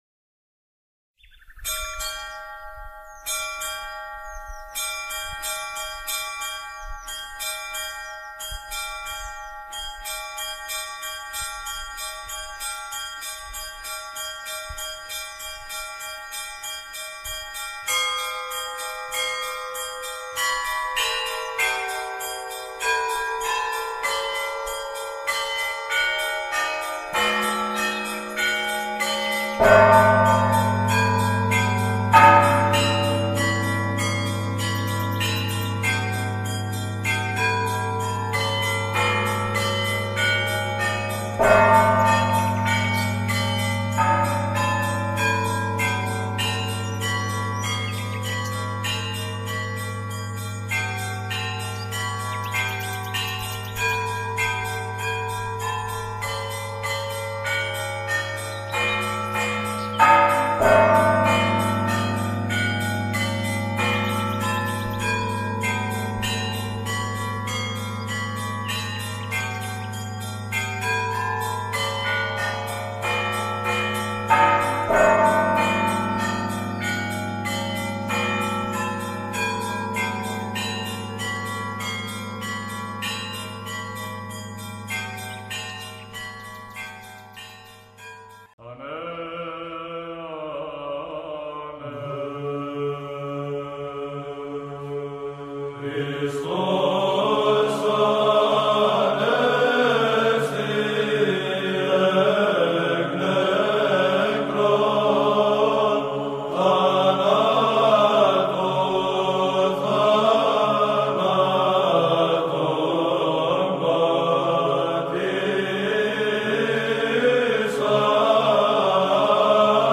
Σᾶς προτείνουμε νὰ ἀκούσετε μία ἠχογράφηση τοῦ Εὐαγγελίου τοῦ Ἑσπερινοῦ τῆς Ἀγάπης σὲ διάφορες γλῶσσες: ἑλληνική, ὁλλανδική, γαλλική, γερμανική, ἀγγλική, λατινική, σλαβική, ἀραβική, ρουμανική, πολωνική, ὁμηρική. Μὲ τὴν συμμετοχὴ ἱερέων καὶ νέων τῆς Ἱερᾶς Μητροπόλεως.